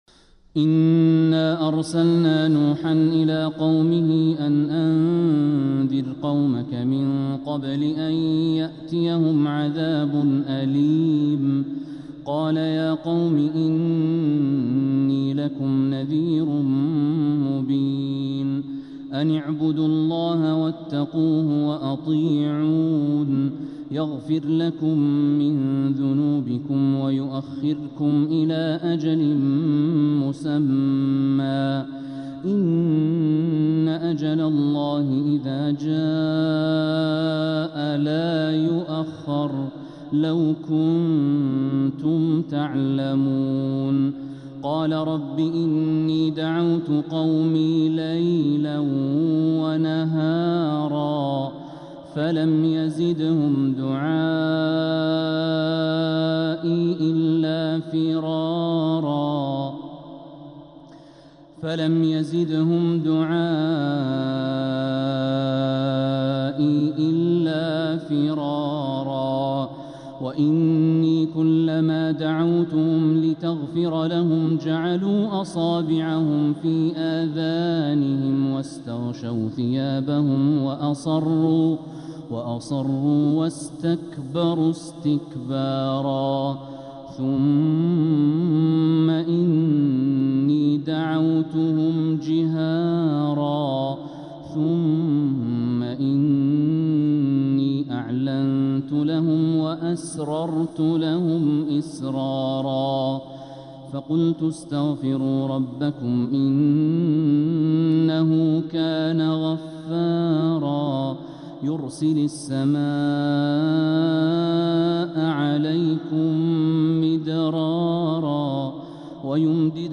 سورة نوح كاملة | شعبان 1447هـ > السور المكتملة للشيخ الوليد الشمسان من الحرم المكي 🕋 > السور المكتملة 🕋 > المزيد - تلاوات الحرمين